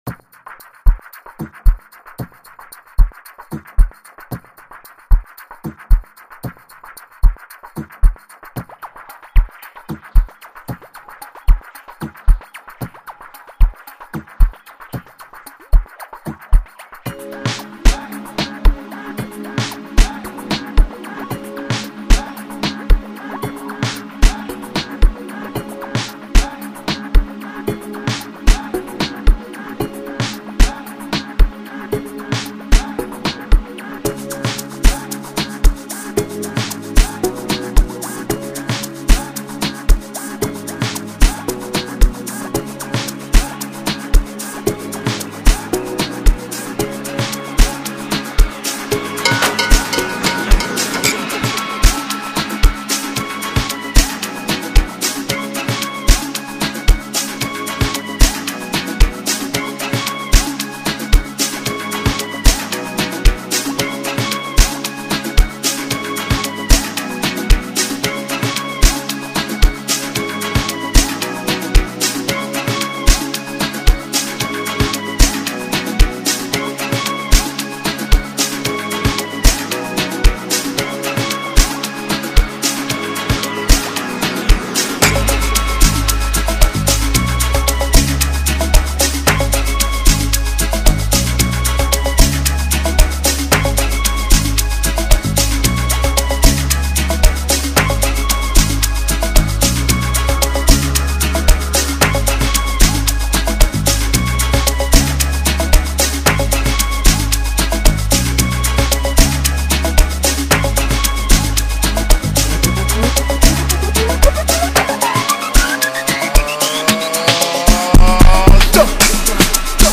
AMAPIANO Apr 07, 2026